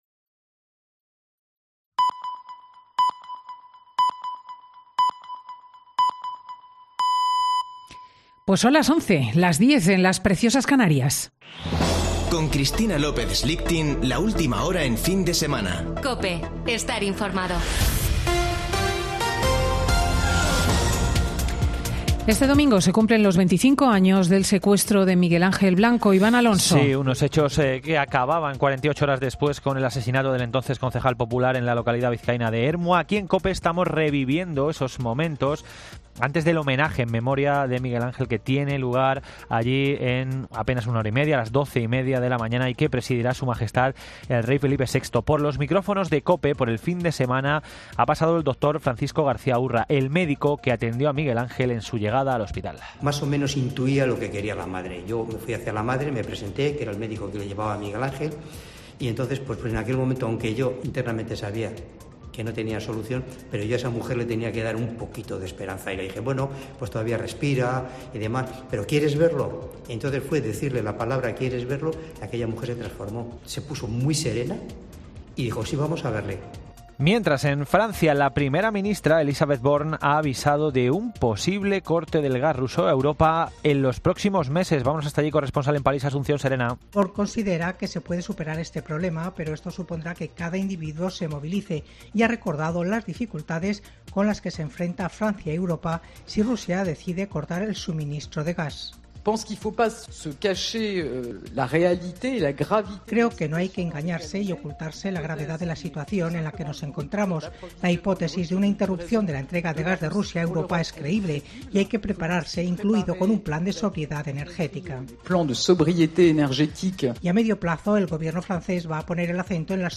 Boletín de noticias de COPE del 10 de julio de 2022 a las 11:00 horas